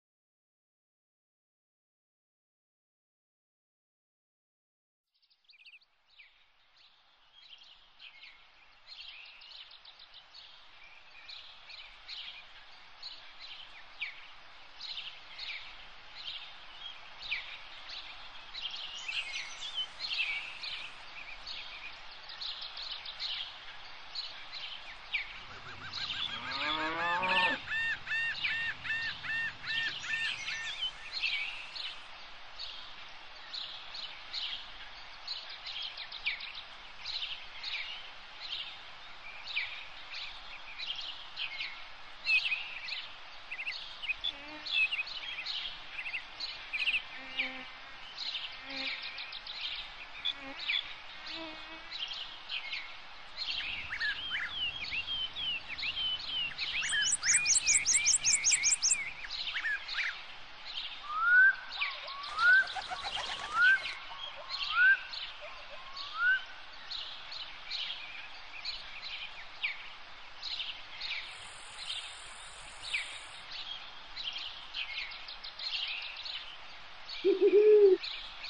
Woodland – Nature Sounds (1 Hour)
Nature sounds are recorded & designed to help people sleep, allowing you to relax and enjoy the sounds of nature while you rest or focus with no adverts or interruptions.
The calming sounds of nature are a natural release for the body.
Please Listen to the Below Sample of Woodland